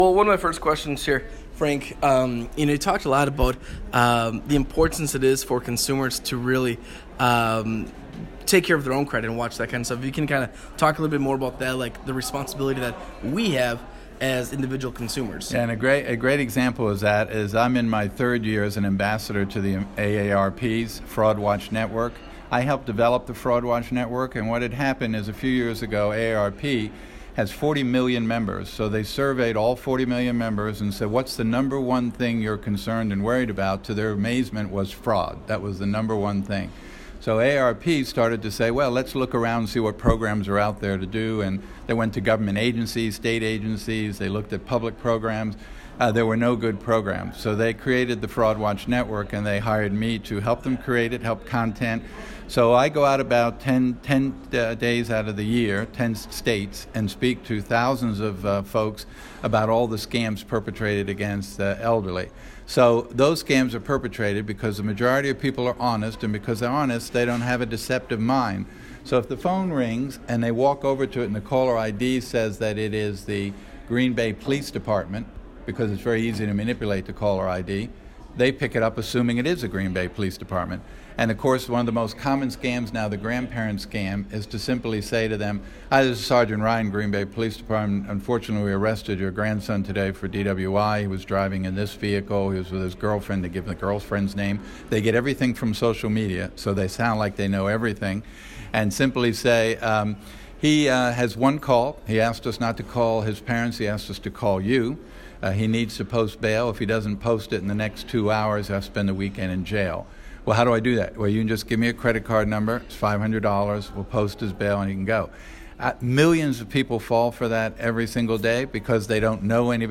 Frank Abignale was in Manitowoc for the CEO Breakfast Series at Silver Lake College on October 4th, 2017.